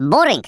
Worms speechbanks
boring.wav